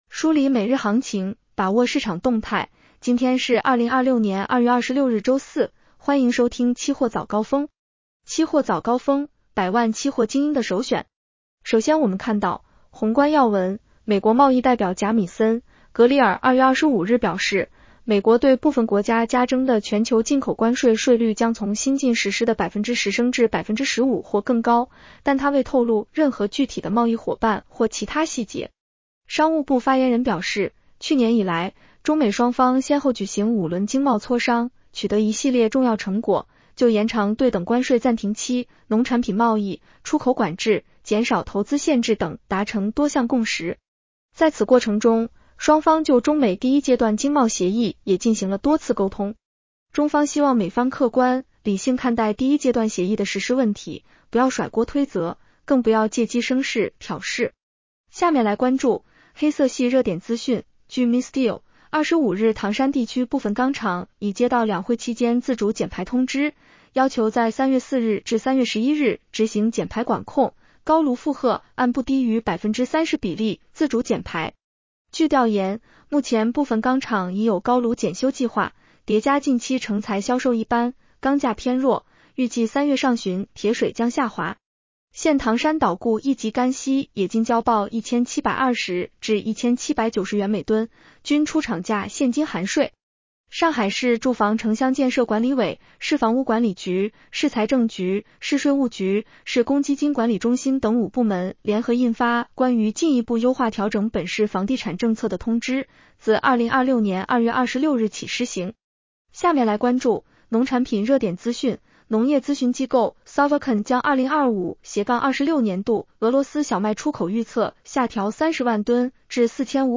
期货早高峰-音频版